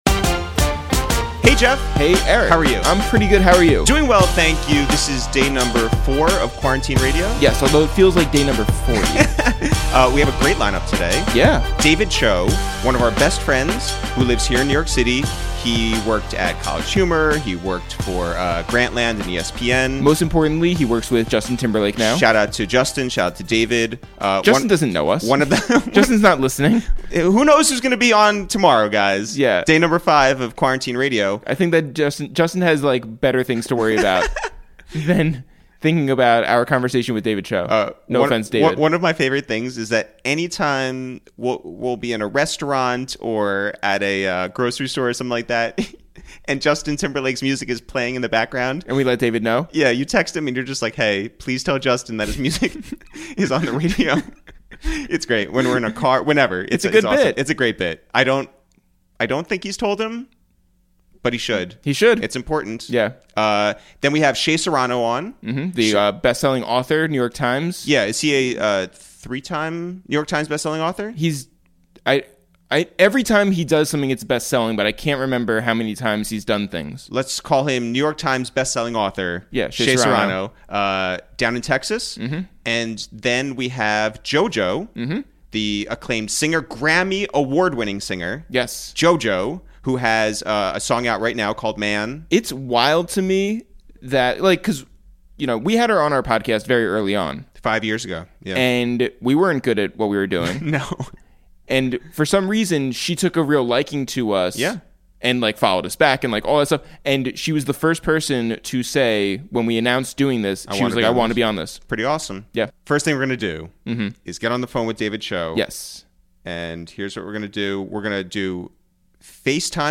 Today on Episode 4 of Quarantine Radio, we make calls from our Upper West Side apartment to check in on NYT Best-Selling Author Shea Serrano